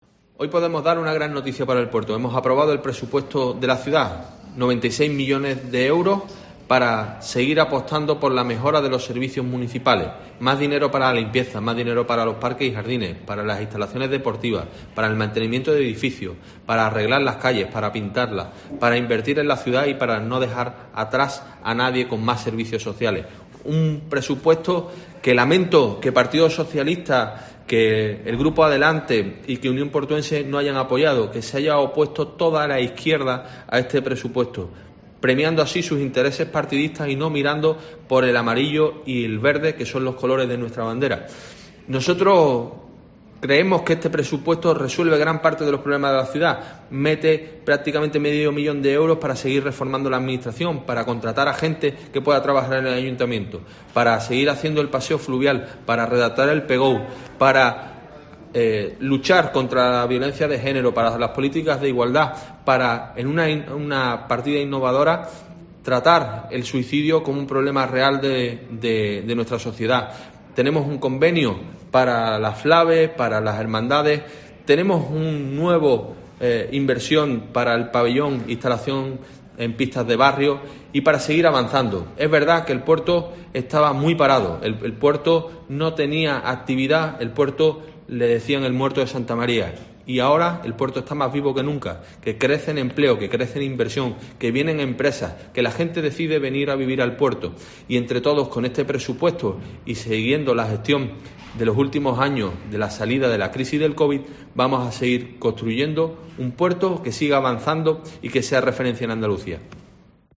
Germán Beardo, alcalde de El Puerto de Santa María, habla sobre la aprobación de los presupuestos municipales